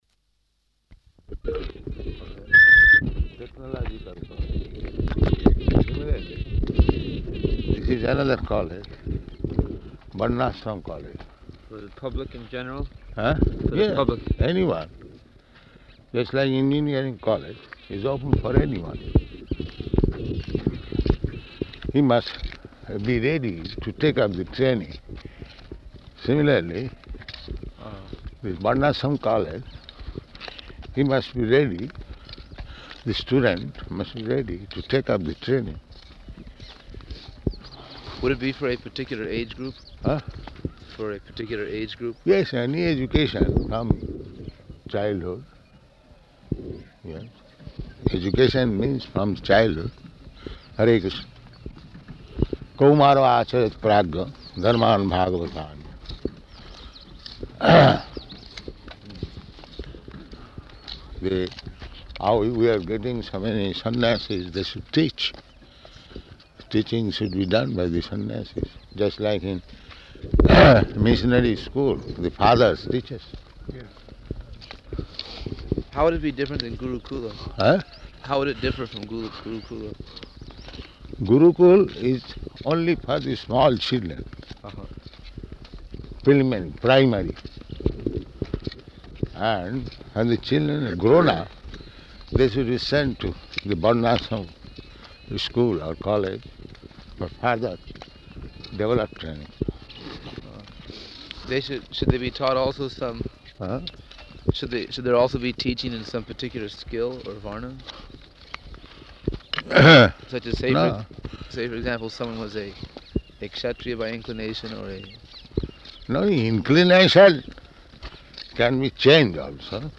Morning Walk, "Varṇāśrama College"
Morning Walk, "Varṇāśrama College" --:-- --:-- Type: Walk Dated: March 14th 1974 Location: Vṛndāvana Audio file: 740314MW.VRN.mp3 Prabhupāda: ...technological college.